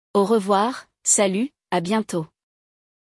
Neste episódio do Walk ‘n’ Talk Francês, você vai ouvir uma conversa bem rápida, mas cheia de palavrinhas do dia a dia de quem fala francês: os cumprimentos.